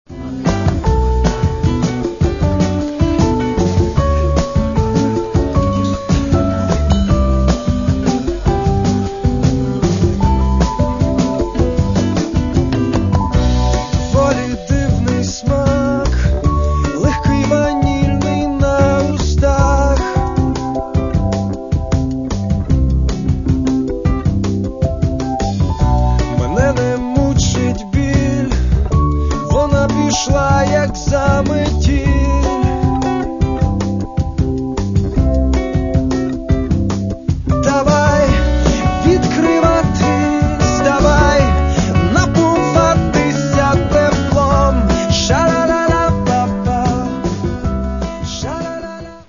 Каталог -> Рок и альтернатива -> Поп рок
Это, кажется, снова легкий рок? – да.